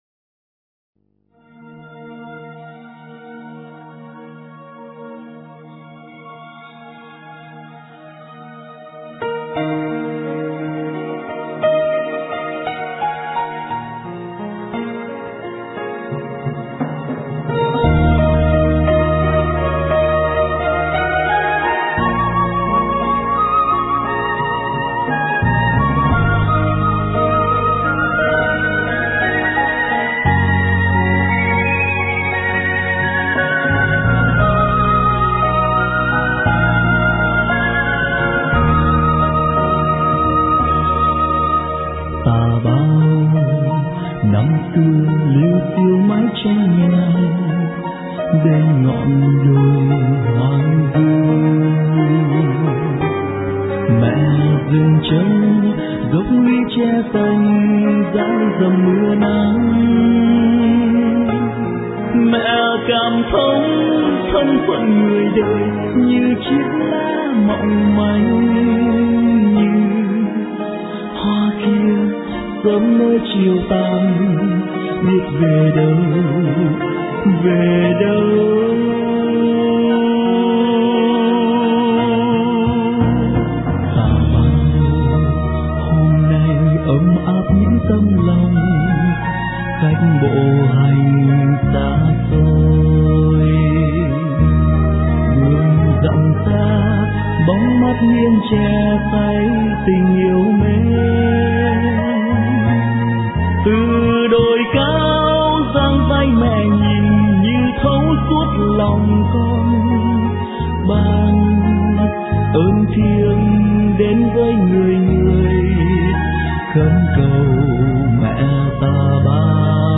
* Thể loại: Đức Mẹ